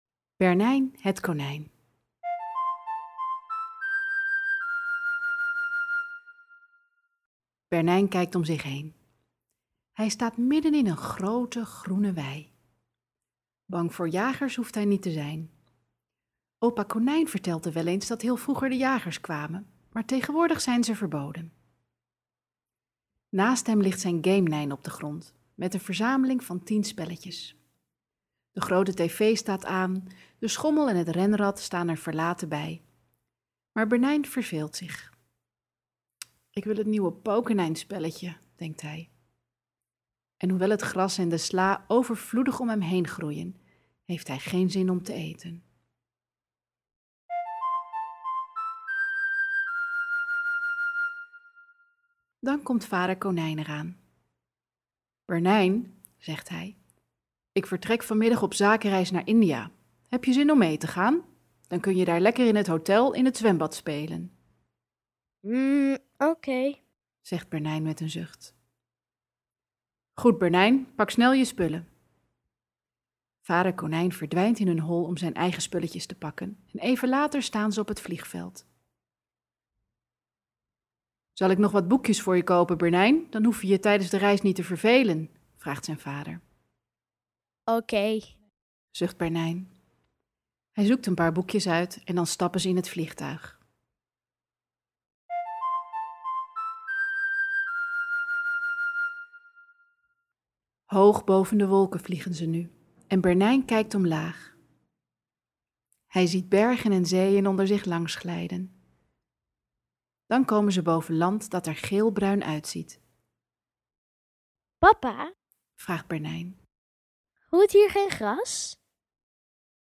Dit luisterboek staat vol met positieve gedachten (oftewel 'PosiWords'): als je er naar luistert voel je je meteen al goed! En met de aanstekelijke muziekjes erachter is het ook nog eens leuk om naar te luisteren.
Op het luisterboek staan 3 verhaaltjes, 3 sets van affirmaties op muziek en 9 rijmpjes, zoals Ik ben moedig: